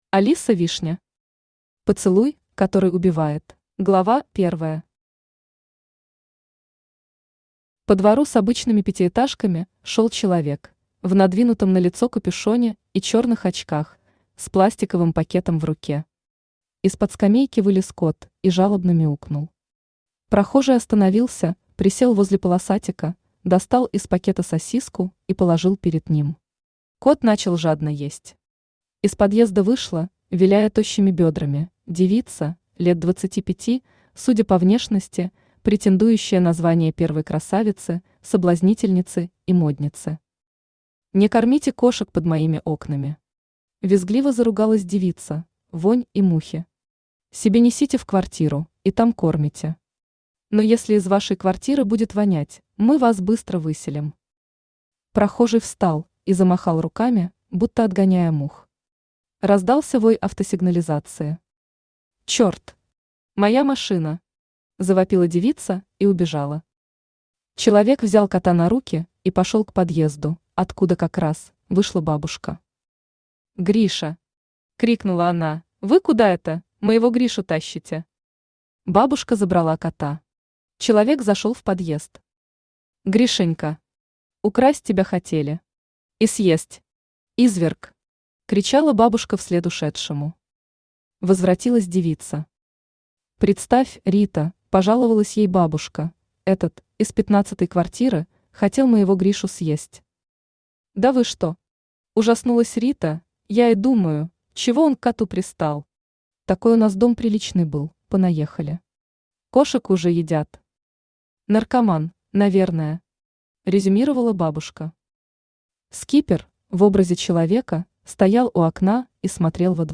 Aудиокнига Поцелуй, который убивает Автор Алиса Вишня Читает аудиокнигу Авточтец ЛитРес.